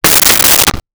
Toilet Seat Fall 01
Toilet Seat Fall 01.wav